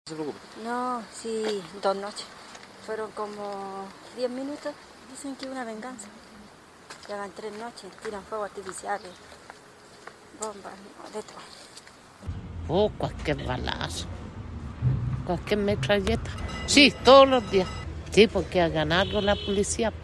Vecinas del sector conversaron con Radio Bío Bío e indicaron que la balacera duró cerca de 10 minutos y que todo sería por venganza.